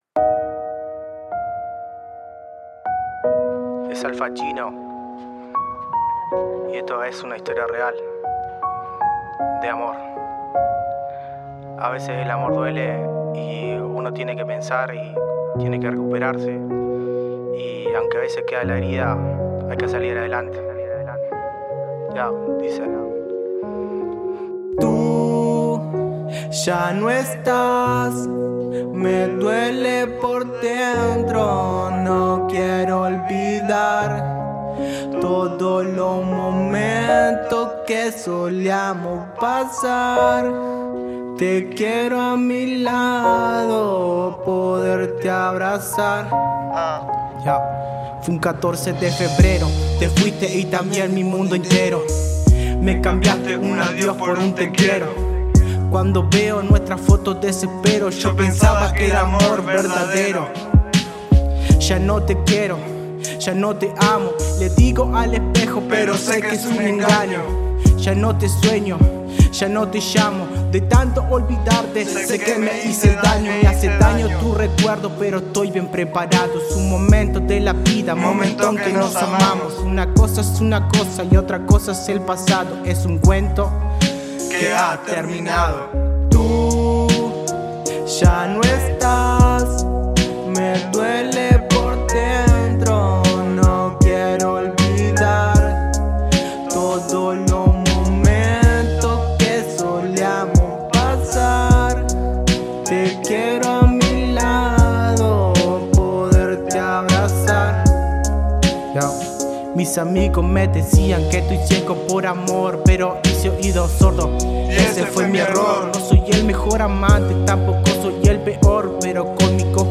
Desde la Gerencia de Formación Cultural, con el objetivo de potenciar las instancias formativas y en coordinación con la Usina Cultural de Parque del Plata, se grabaron productos musicales de los participantes de los talleres que se llevan a cabo en el departamento.
13._hip_hop_colonia_nicolich.mp3